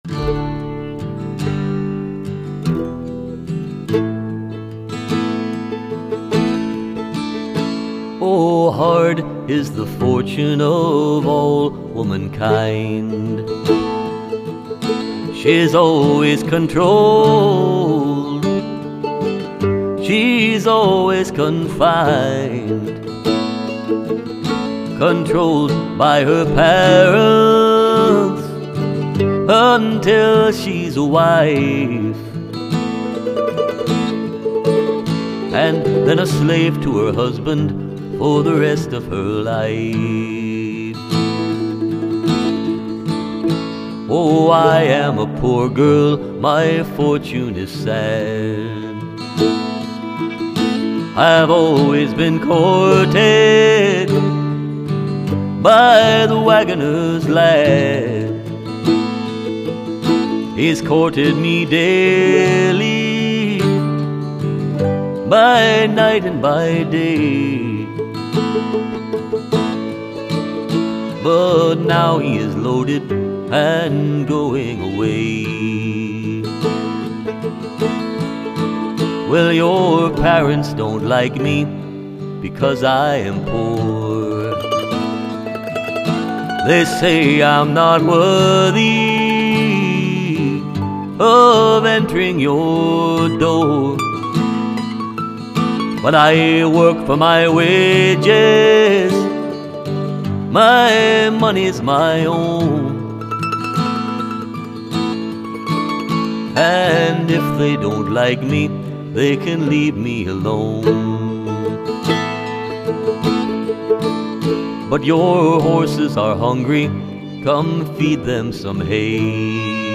mandolin
fiddle and harmony vocals